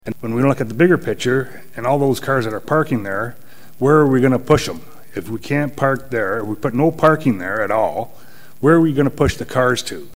Councillor Brad Nieman felt the report didn’t follow the proper process by going to the traffic committee first before coming to council.